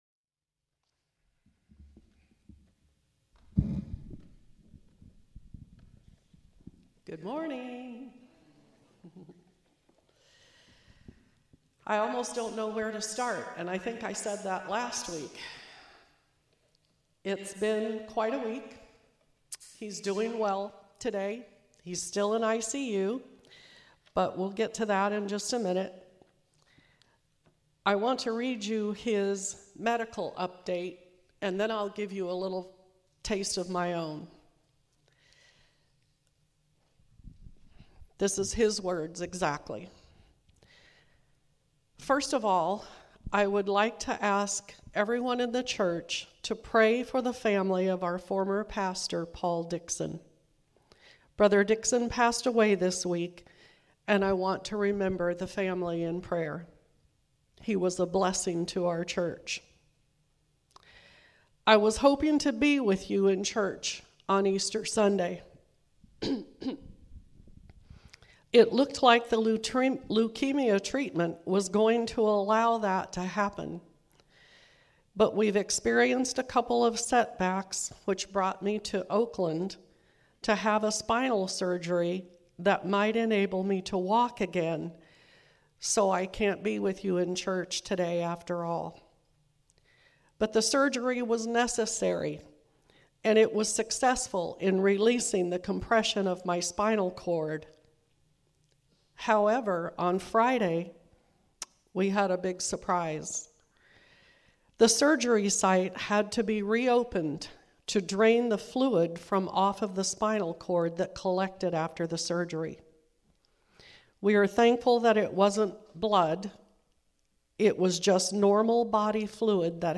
Easter Sunday Service
Morning Sermons